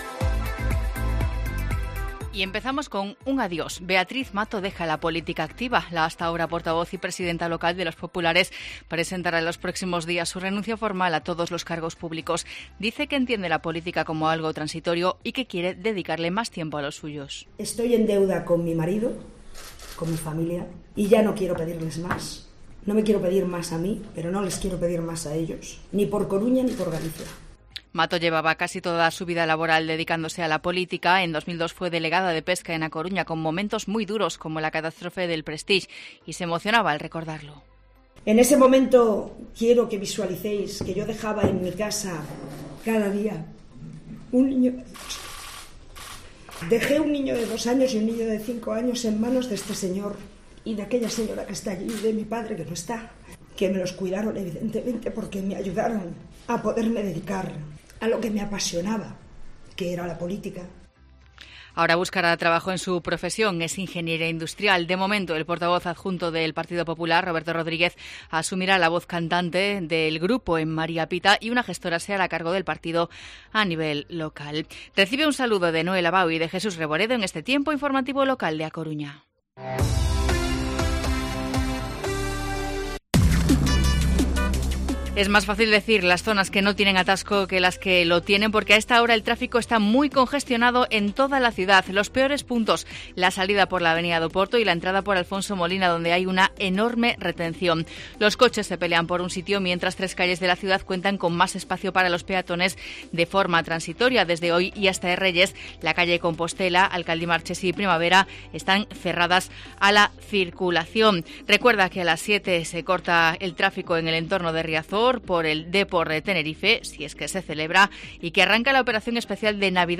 Informativo Mediodía COPE Coruña viernes, 20 de diciembre de 2019 14:20-14:30